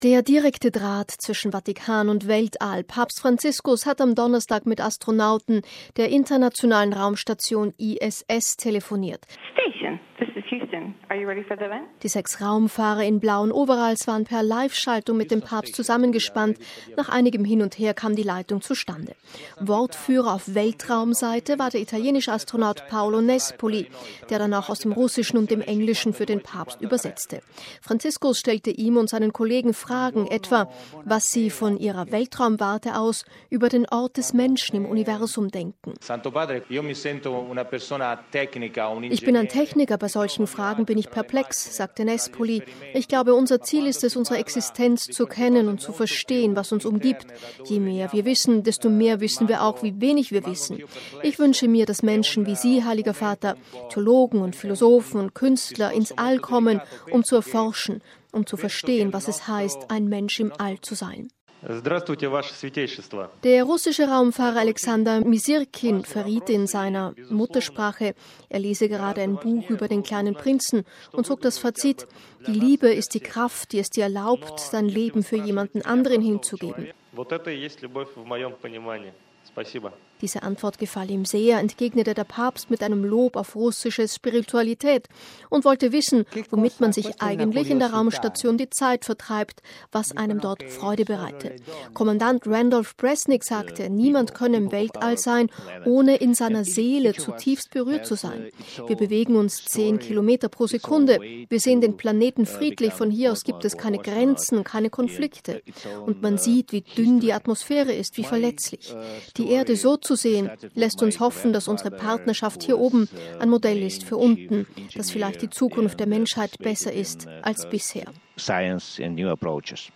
Die sechs Raumfahrer in blauen Overalls waren per Live-Schaltung mit dem Papst zusammengespannt, nach einigem Hin und Her kam die Leitung zustande. Wortführer auf Weltraum-Seite war der italienische Astronaut Paolo Nespoli, der dann auch aus dem Russischen und dem Englischen für den Papst übersetzte. Franziskus stellte ihm und seinen Kollegen Fragen, etwa, was sie von ihrer Weltraum-Warte aus über den Ort des Menschen im Universum denken.
Franziskus saß während der Live-Schalte ins Weltall im Empfangsraum der Audienzhalle an einem Schreibtisch, vor sich einen großen Bildschirm, auf dem die sechs Astronauten schwebten.